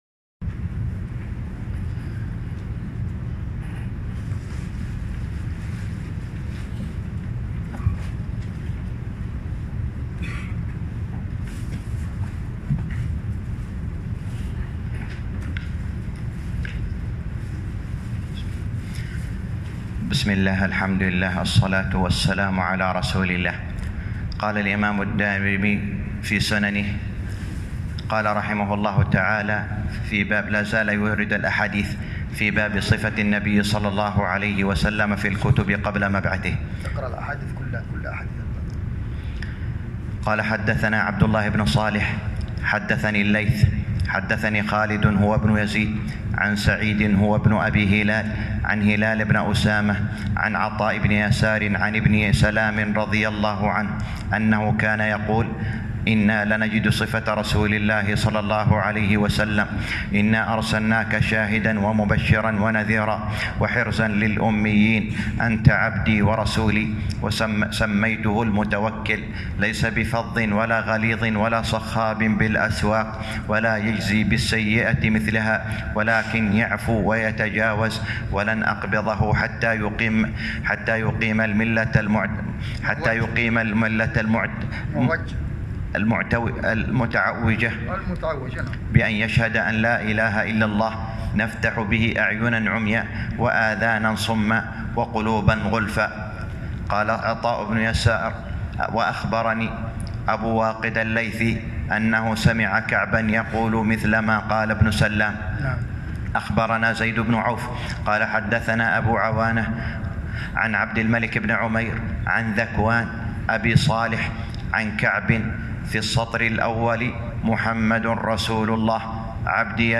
الدرس الرابع - شرح سنن الدارمي الباب الثاني _ 4